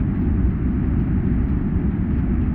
roadstones_rf1V8_ex.wav